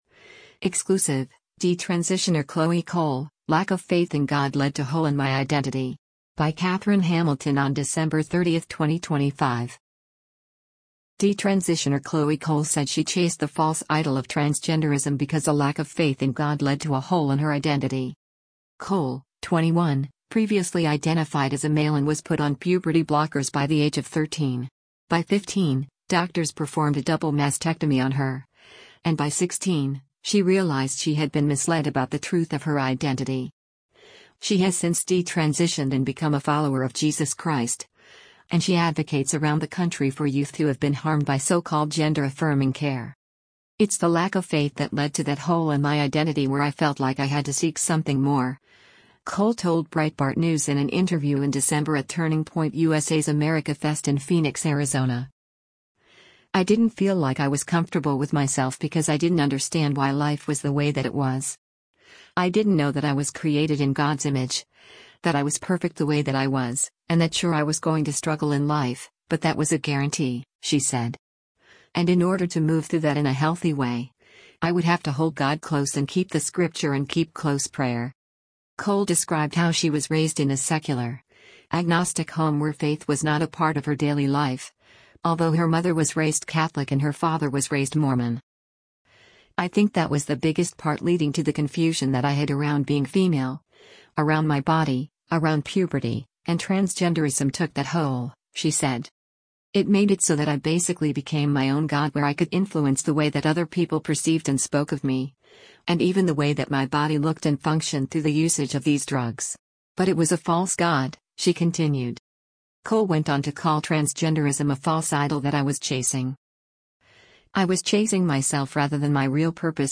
“It’s the lack of faith that led to that hole in my identity where I felt like I had to seek something more,” Cole told Breitbart News in an interview in December at Turning Point USA’s AmericaFest in Phoenix, Arizona.